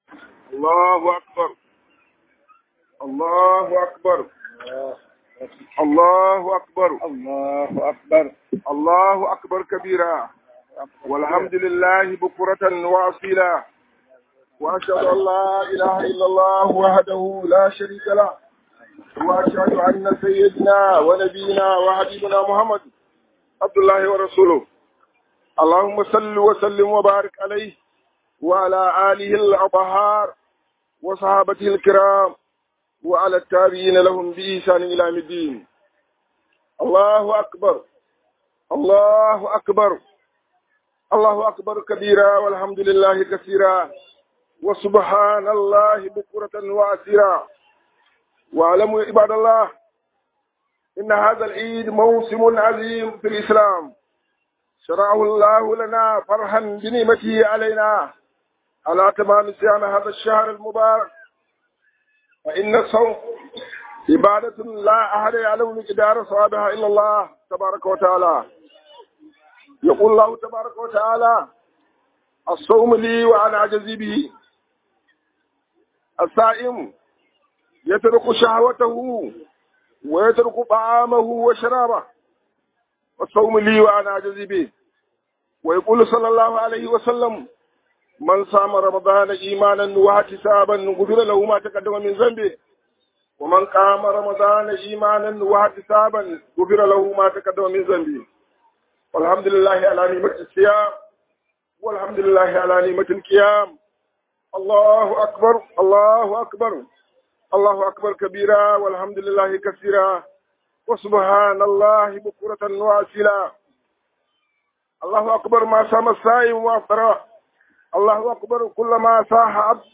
HUDUBAR IDIL FIDIR 2023 1.mp3